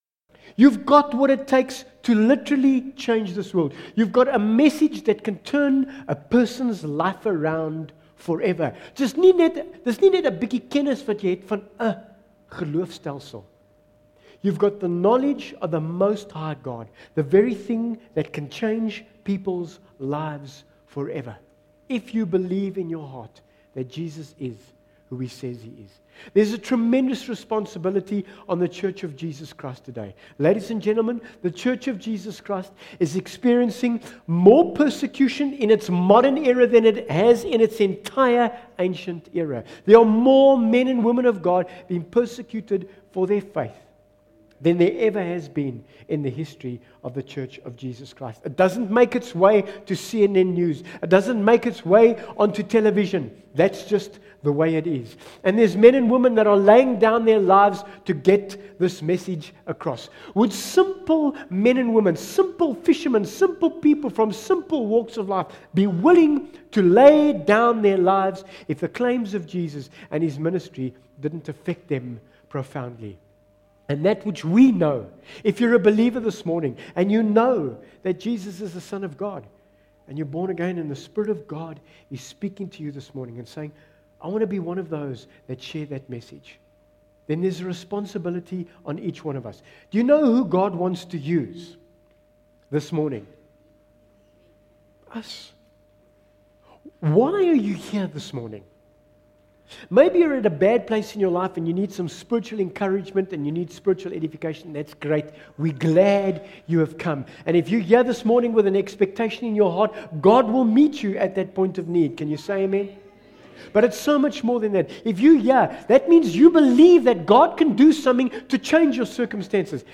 Bible Text: MATTHEW 28:18-20 | Preacher